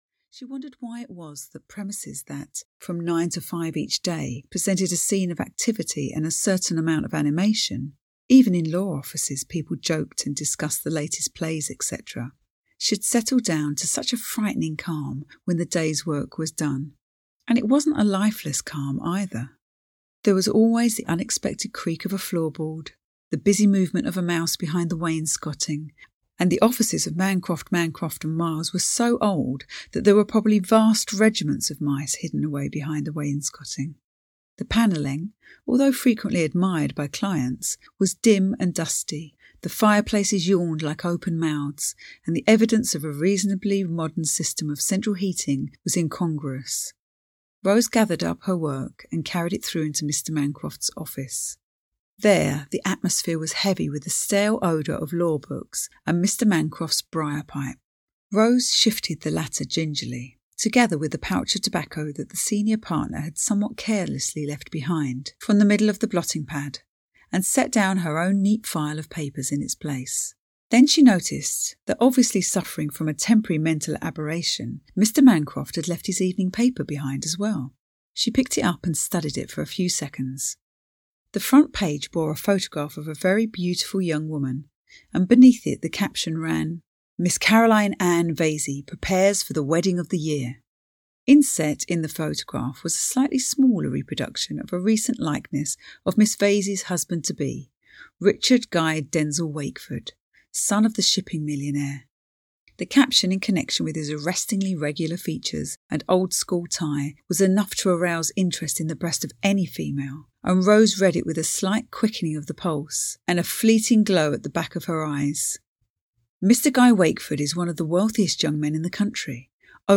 Audio knihaEscape to Happiness (EN)
Ukázka z knihy